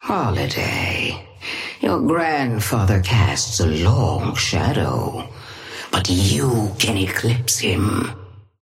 Sapphire flame voice line - Holliday, your grandfather casts a long shadow, but you can eclipse him.
Patron_female_ally_astro_start_01.mp3